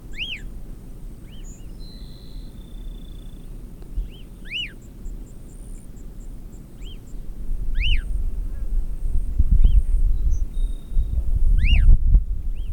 LESSER ELAENIA Elaenia chiriquensis
whistle calls in response to playback recorded Estancia Laguna Blanca, Departamento San Pedro
Elaeniachiriquensis15dec09.wav